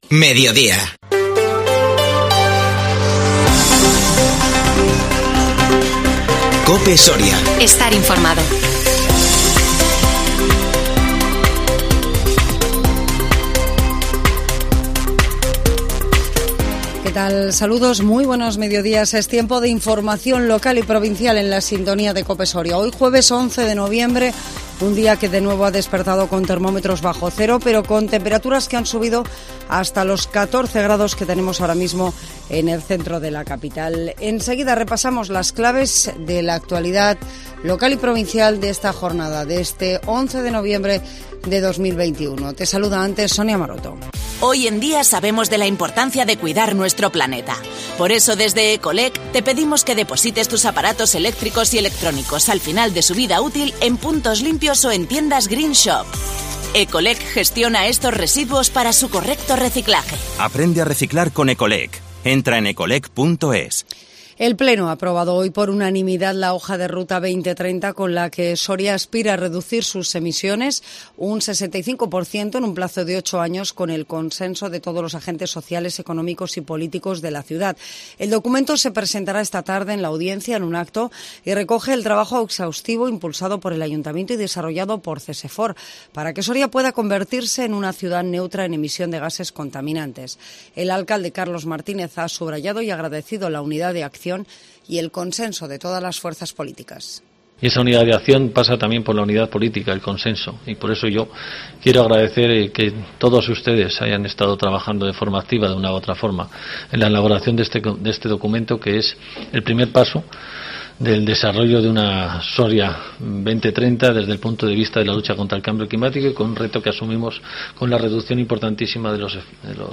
INFORMATIVO MEDIODÍA 11 NOVIEMBRE 2021